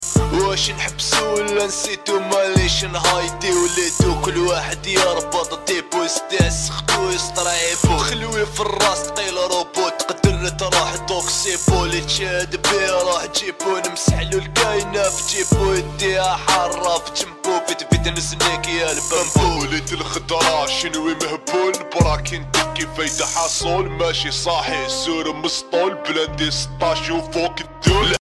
rap algérien